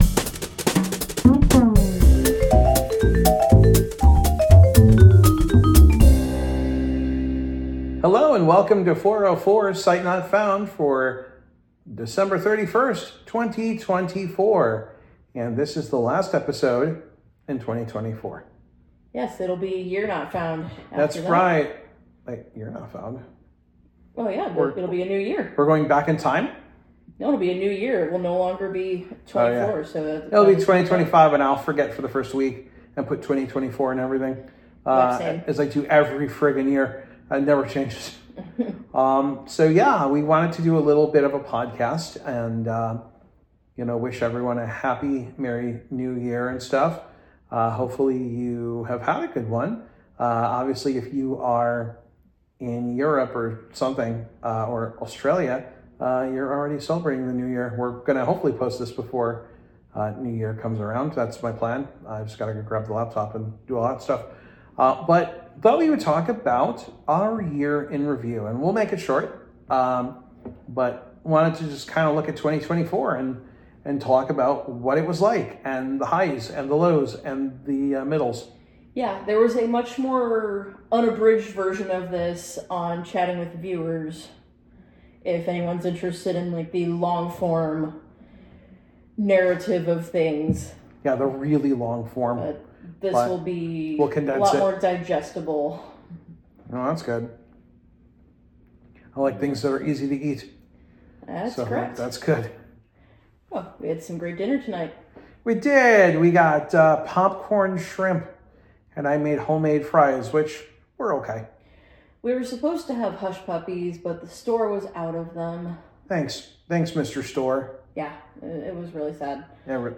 Join two blind people as they discuss random topics over coffee.